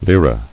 Lire [li·ra] Formerly the basic unit of money in Italy; equal to 100 centesimi ... pl. of Lira ...